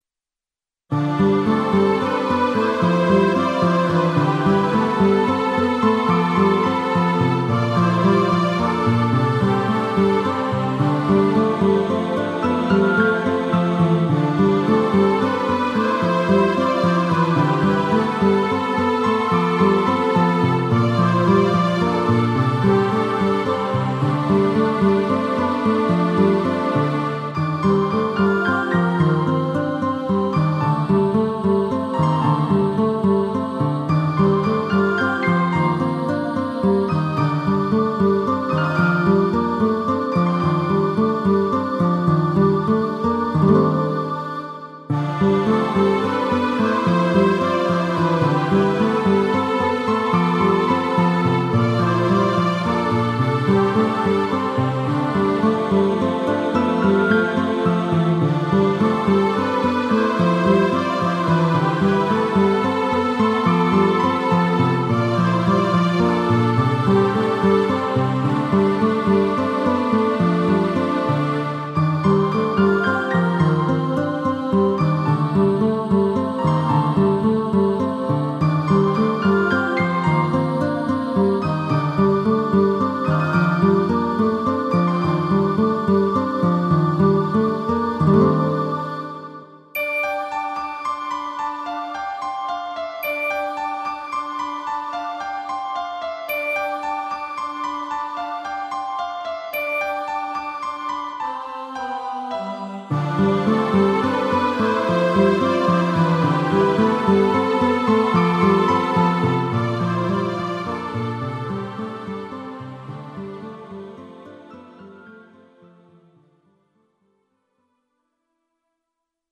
エレクトーンでゲーム音楽を弾きちらすコーナー。